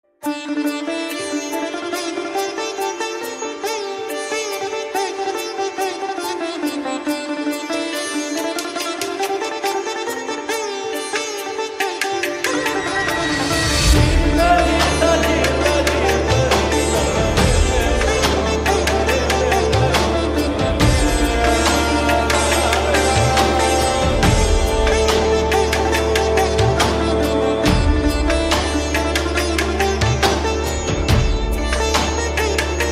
Flute Bgm Download .mp3